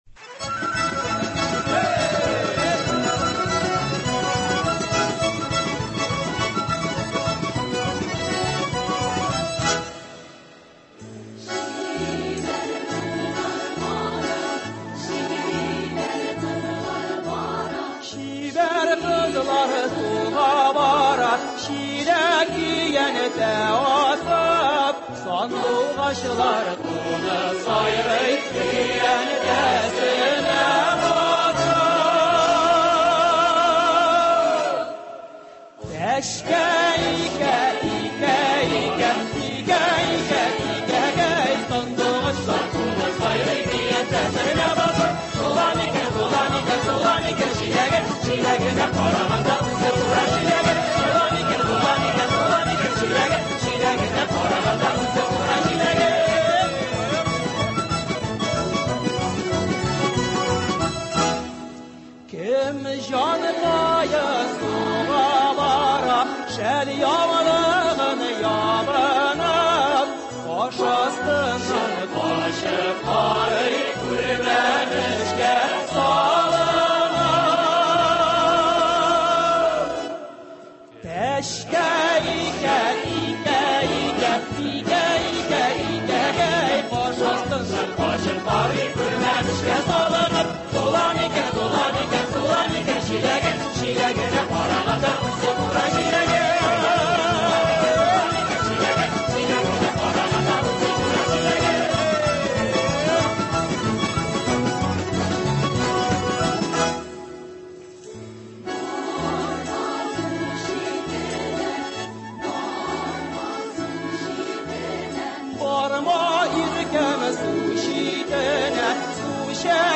Студиябезнең бүгенге кунагы шулай ук фән кешесе.